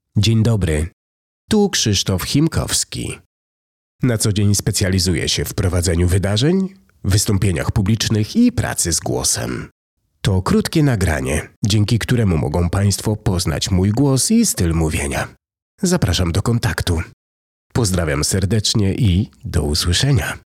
2. Głos, który buduje emocje
Wasza ceremonia będzie brzmiała tak, jak powinna, czyli ciepło, wyraźnie i z emocjami, które i mi się udzielają.
Pracuję spokojnie, z wyczuciem i pełną kontrolą nad tym, co dzieje się w danym momencie.